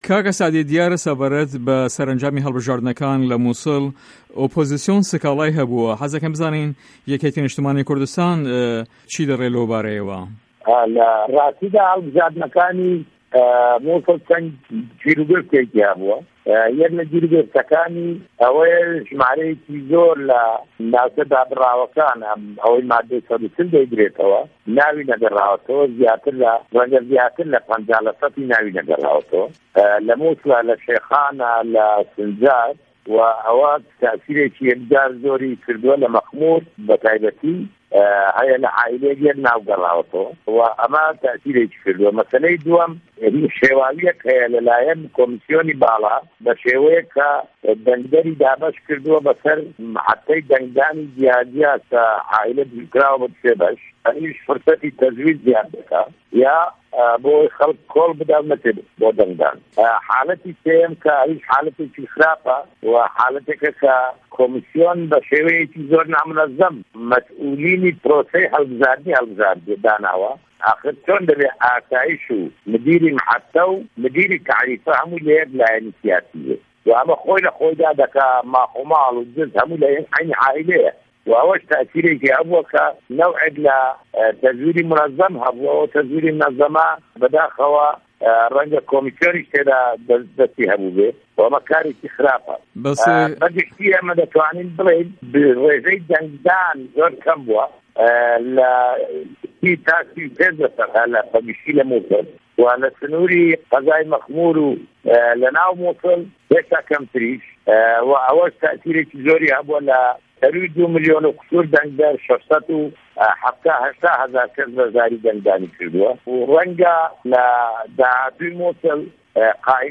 وتووێژ له‌گه‌ڵ سه‌عدی ئه‌حمه‌د پیره‌